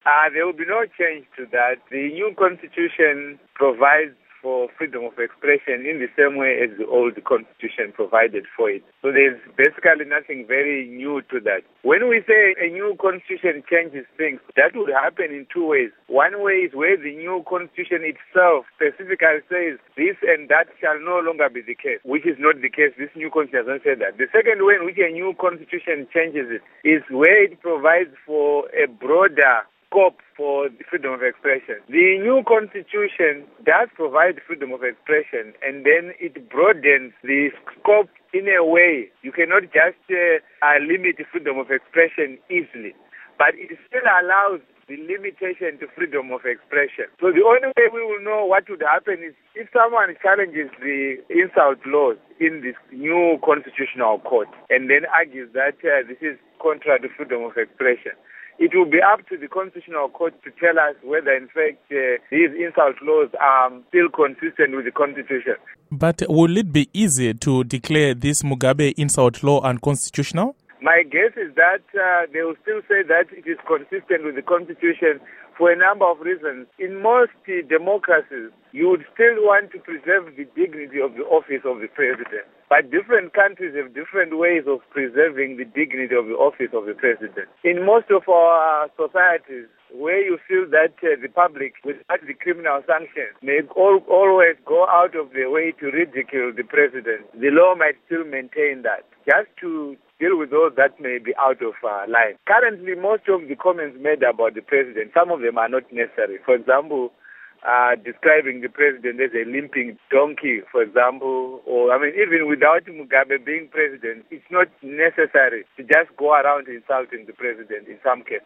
Interview With Lovemore Madhuku